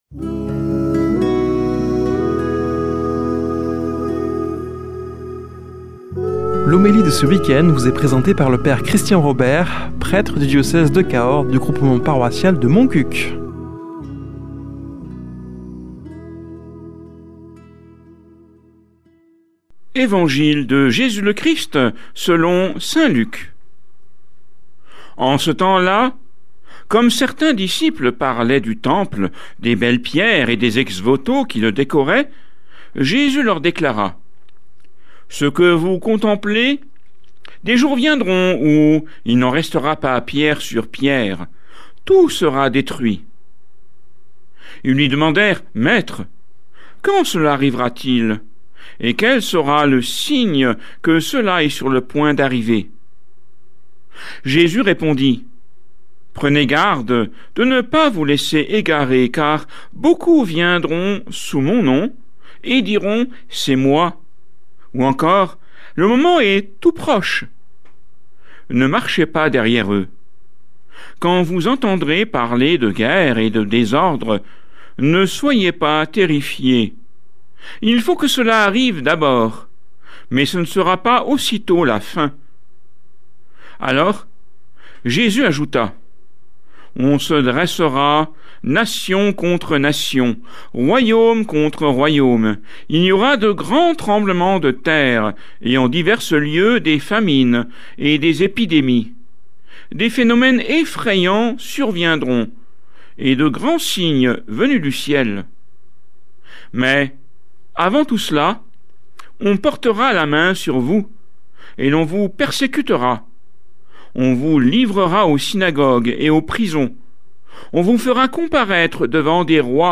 Homélie du 15 nov.